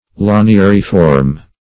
Search Result for " laniariform" : The Collaborative International Dictionary of English v.0.48: Laniariform \La`ni*ar"i*form\, a. [Laniary + -form.]